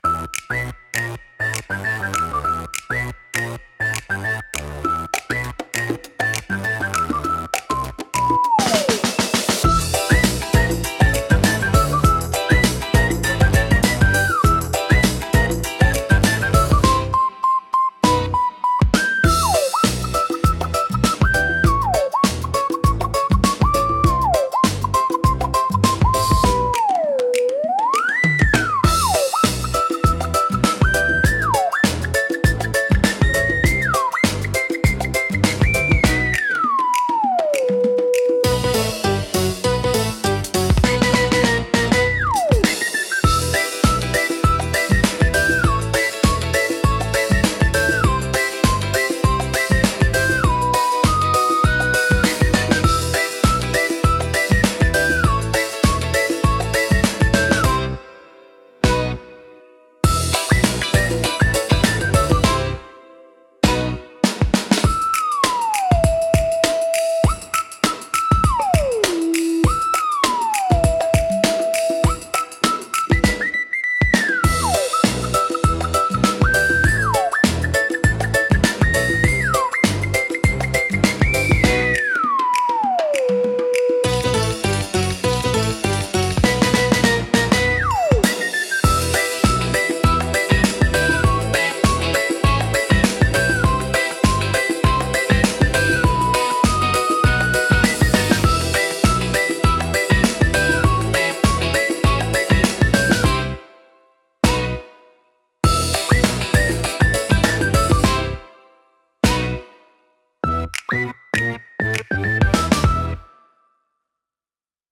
軽快なリズムと遊び心あふれるメロディが、聴く人に楽しさと自由なエネルギーを届けます。
気軽で楽しい空気を作り出し、場を明るく盛り上げるジャンルです。